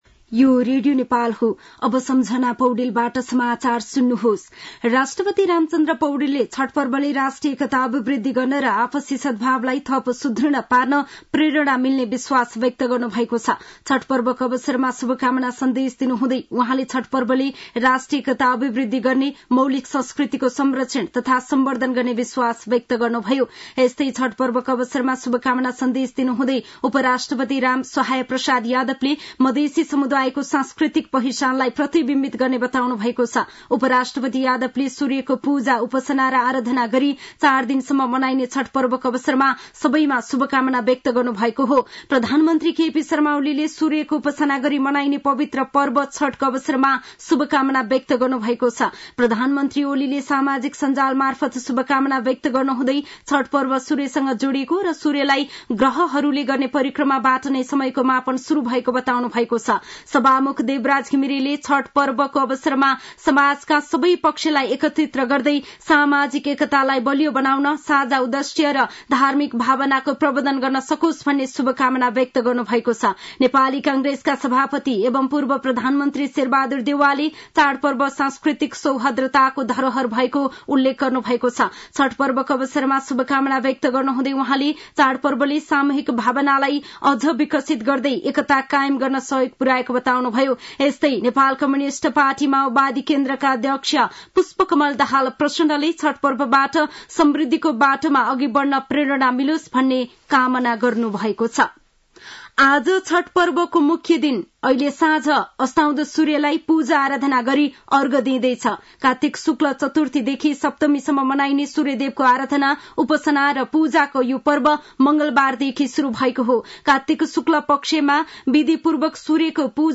साँझ ५ बजेको नेपाली समाचार : २३ कार्तिक , २०८१